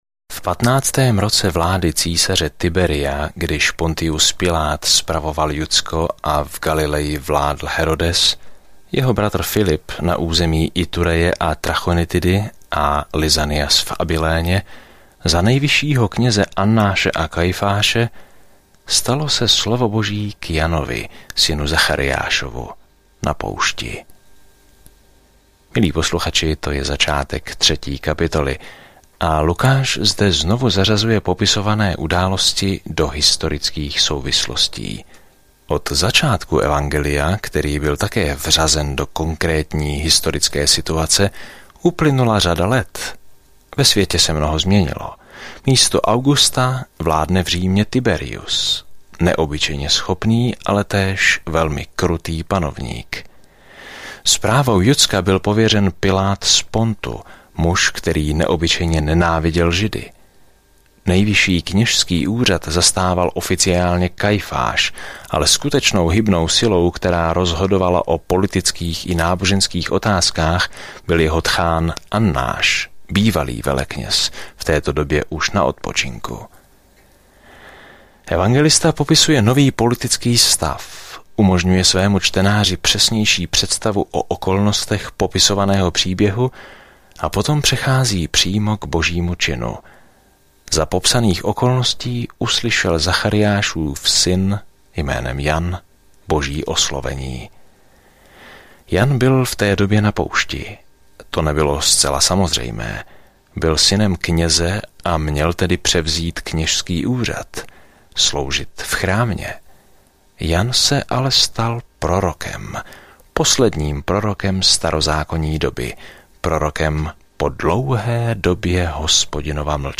Písmo Lukáš 3 Den 5 Začít tento plán Den 7 O tomto plánu Očití svědkové informují o dobré zprávě, kterou Lukáš vypráví o Ježíšově příběhu od narození po smrt až po vzkříšení; Lukáš také převypráví své učení, které změnilo svět. Denně procházejte Lukášem, když posloucháte audiostudii a čtete vybrané verše z Božího slova.